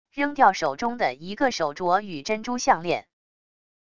扔掉手中的一个手镯与珍珠项链wav音频